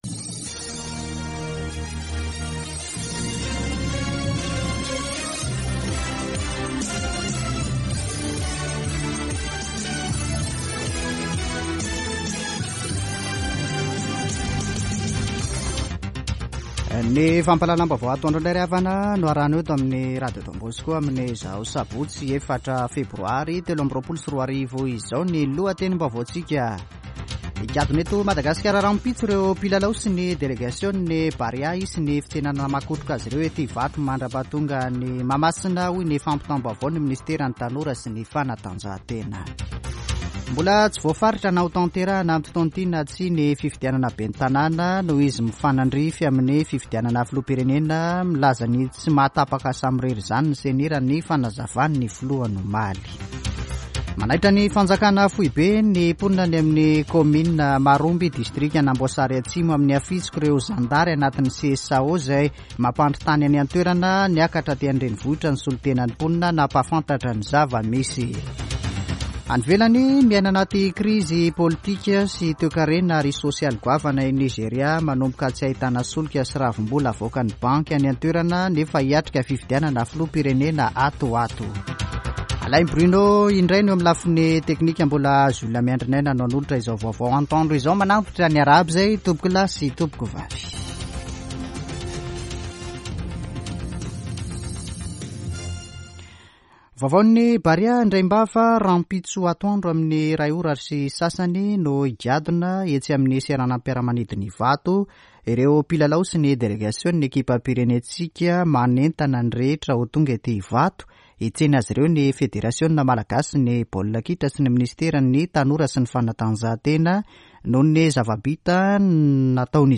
[Vaovao antoandro] Sabotsy 04 febroary 2023